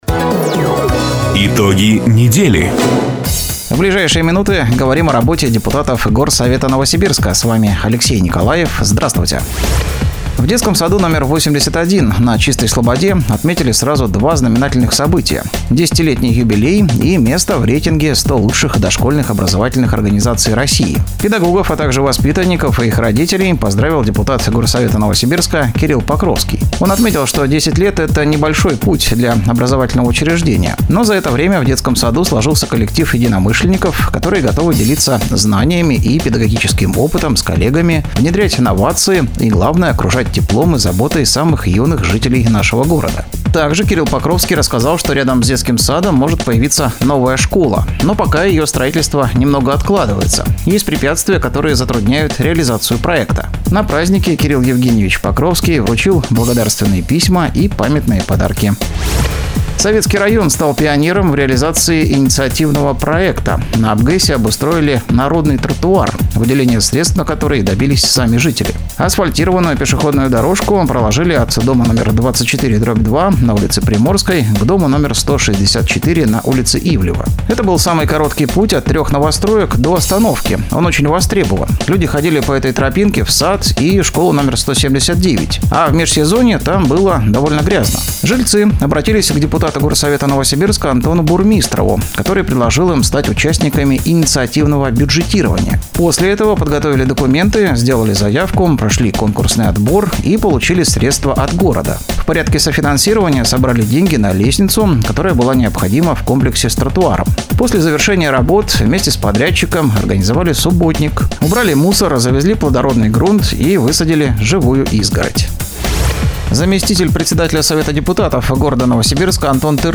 Запись программы "Итоги недели", транслированной радио "Дача" 23 ноября 2024 года.